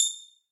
click.ogg